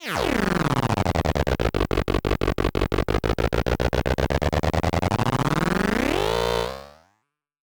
Glitch FX 43.wav